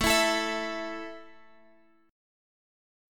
A5 chord {x 12 14 14 x 12} chord